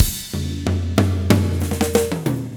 04 rhdrm93tom.wav